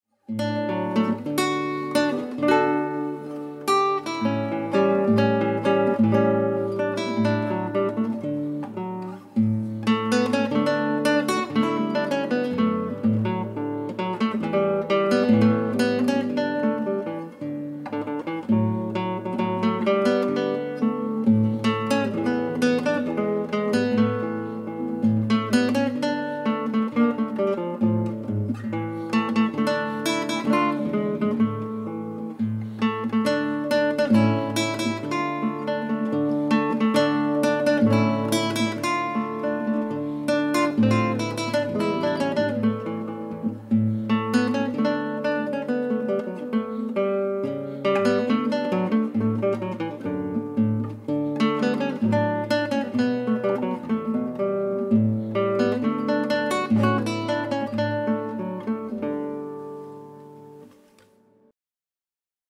Игра на гитаре